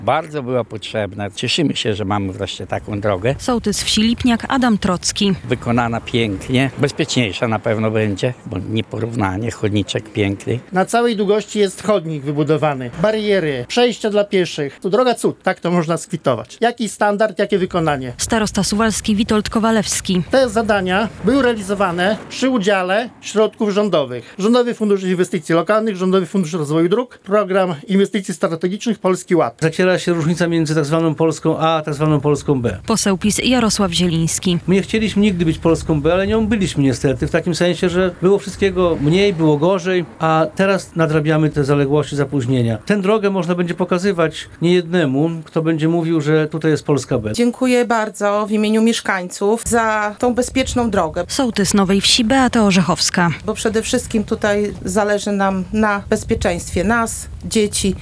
Mieszkańcy Nowej Wsi i Lipniaka mają nową drogę - relacja
We wtorek (26.09) licznie zgromadzeni mieszkańcy wzięli udział w uroczystym otwarciu drogi. 3,5-kilometrowy odcinek prowadzi przez malownicze tereny gminy Suwałki.